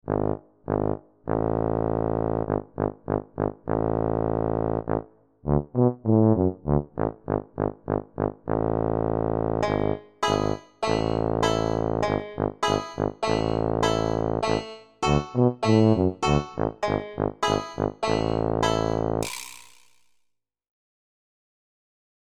Light and Silly
Things are a bit uncomfortable, but not full-on weird.
Am extremely short but useful incidental track.